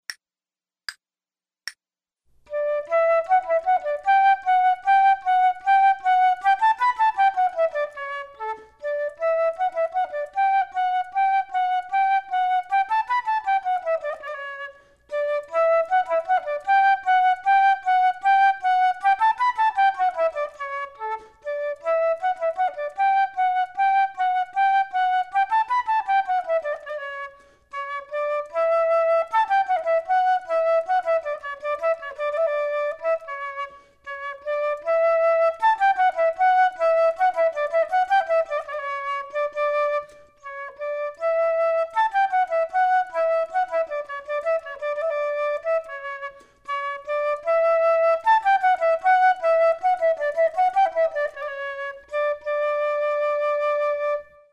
Flute 2 Only: